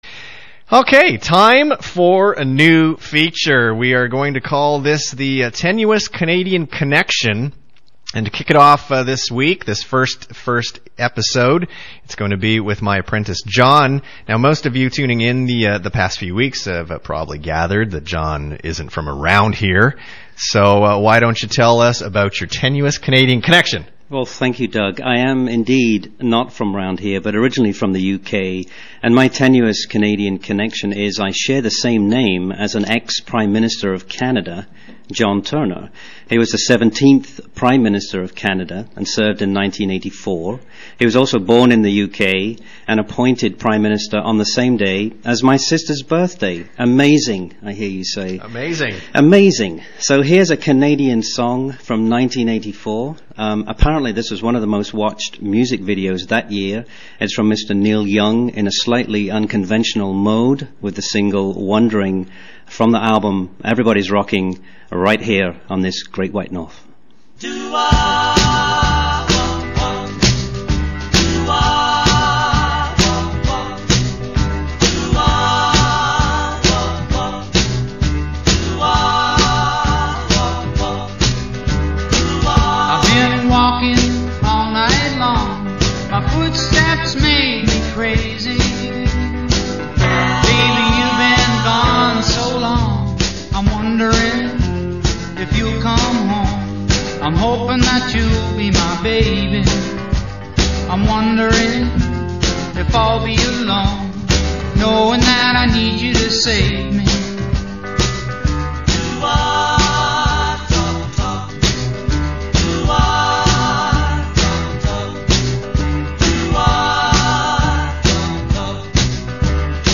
You sound so British!